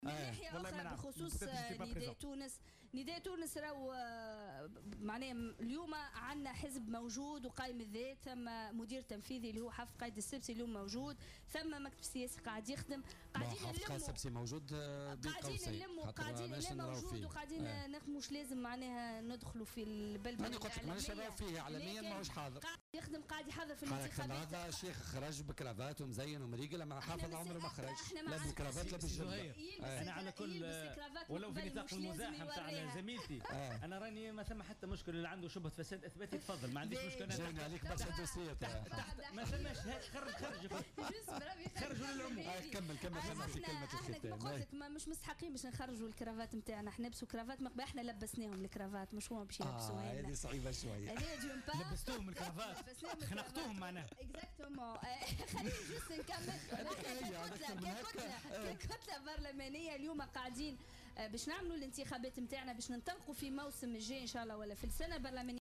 قالت النائب ورئيسة لجنة التحقيق في شبكات التسفير الى بؤر التوتر هالة عمران ضيفة بولتيكا اليوم الإثنين إن نداء تونس بصدد الاستعداد للإنتخابات وهو يستعد للدخول لمرحلة حساسة وهي مرحلة الانتخابات حسب قولها.